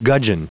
Prononciation du mot gudgeon en anglais (fichier audio)
Prononciation du mot : gudgeon